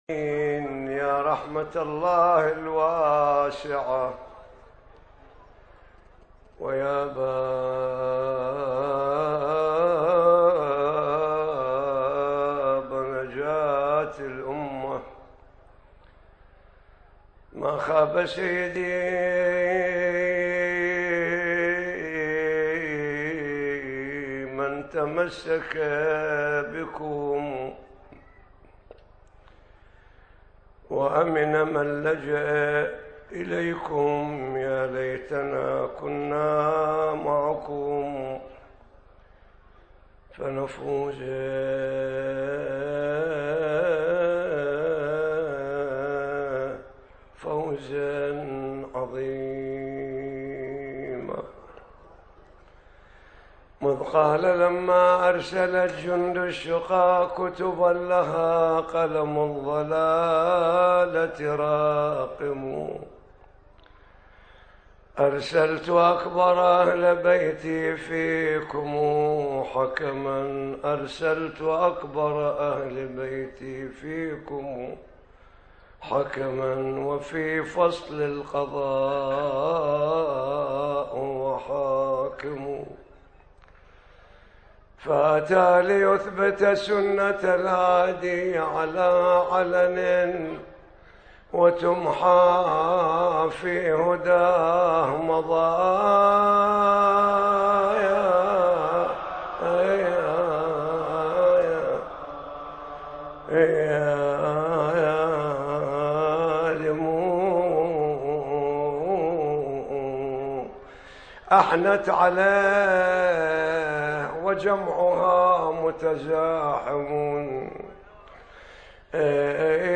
ليلة 5 محرم 1437 هـ المعقل البصرة